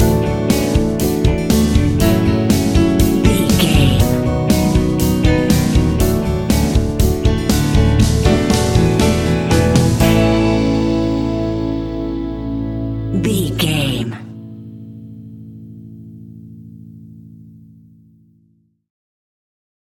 Ionian/Major
pop rock
indie pop
fun
energetic
uplifting
cheesy
instrumentals
upbeat
groovy
guitars
bass
drums
piano
organ